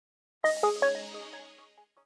Power Off.wav